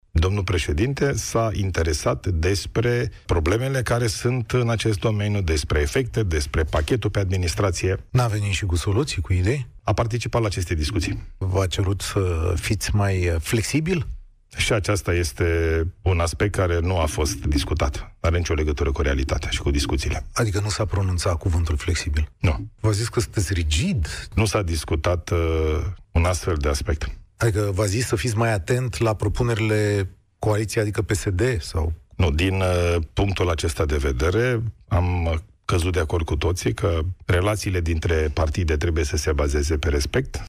Premierul Ilie Bolojan a negat, la Europa FM, informațiile apărute pe surse, potrivit cărora președintele Nicușor Dan ar fi calculat, cu pixul pe hârtie, în cadrul reuniunii de la Cotroceni cu liderii coaliției de guvernare, care este impactul bugetar al concedierilor din administrația locală. Premierul a spus că e vorba de o pseudoștire.